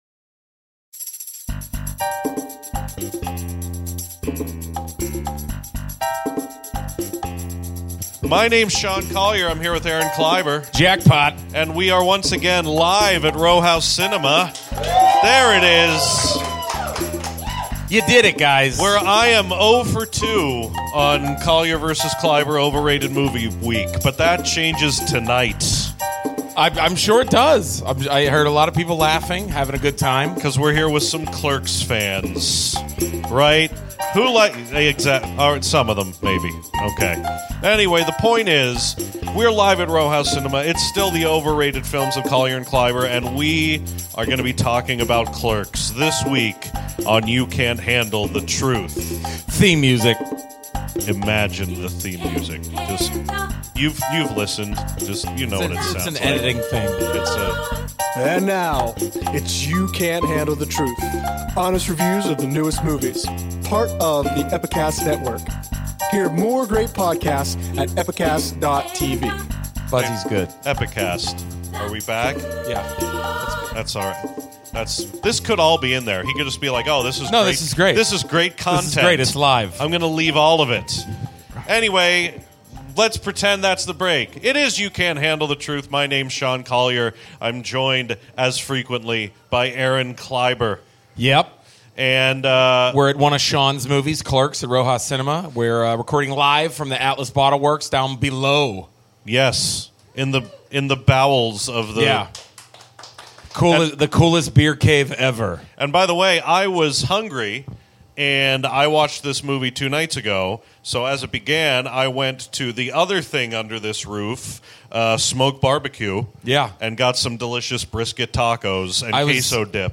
YCHTT #133 — LIVE From Row House: Clerks